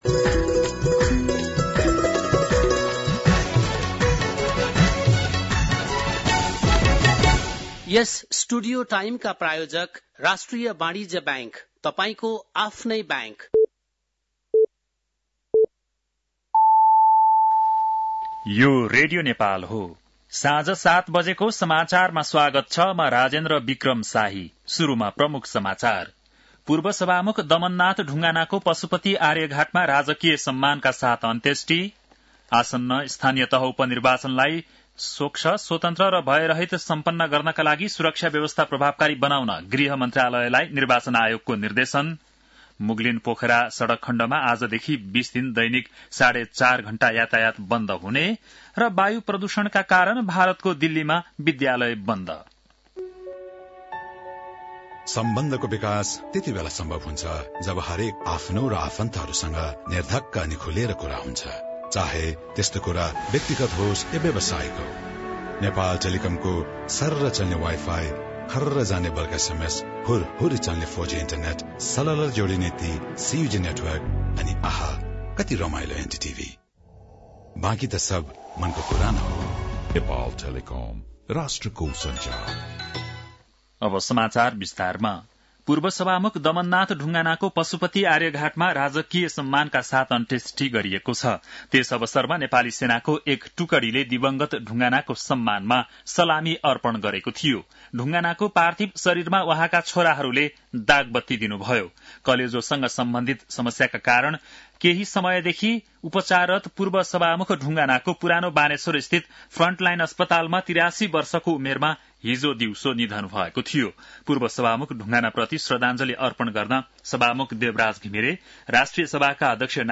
बेलुकी ७ बजेको नेपाली समाचार : ४ मंसिर , २०८१
7-PM-Nepali-NEWS-8-03-.mp3